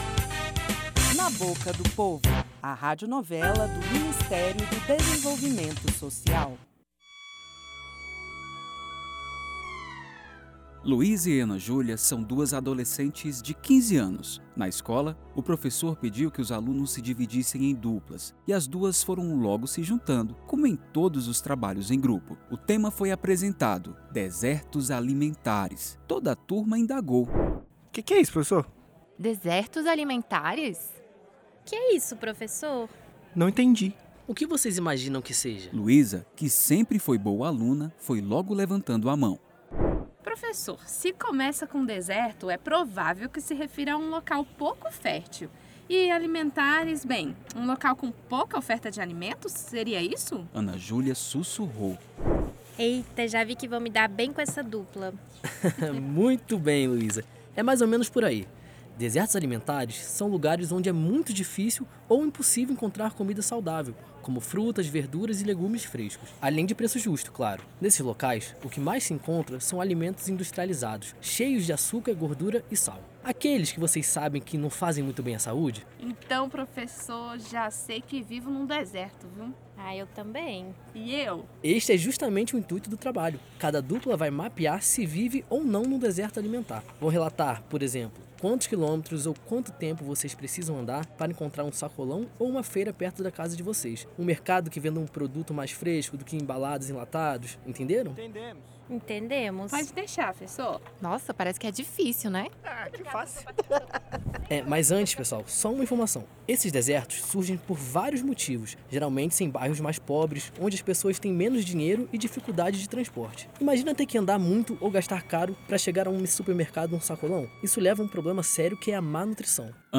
Na Boca do Povo - Radionovela